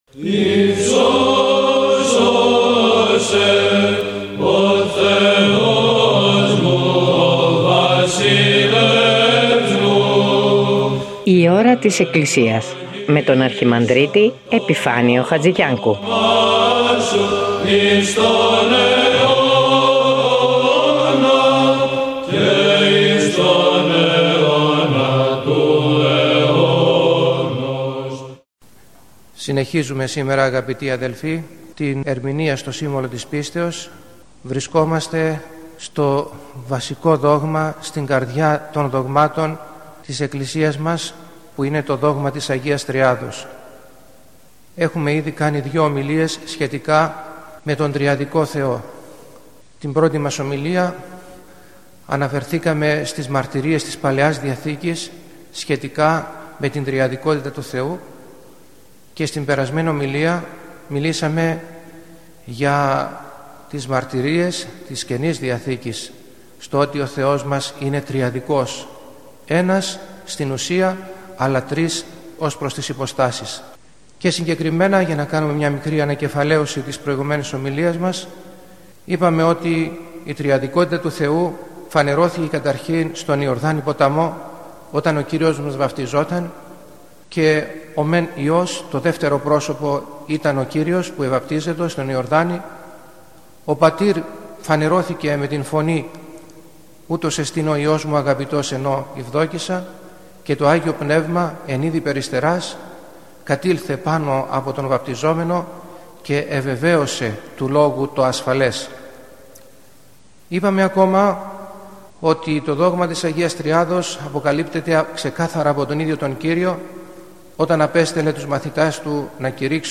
Η παρούσα ομιλία έχει θεματολογία «Το δόγμα της Αγ. Τριάδος».